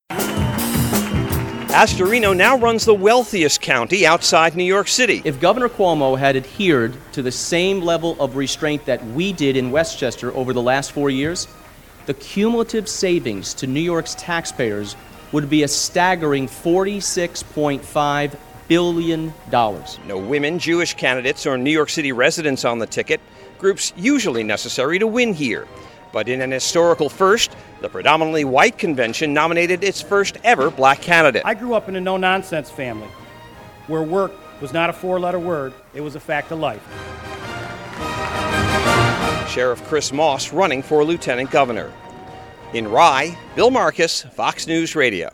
Here’s my report from that event: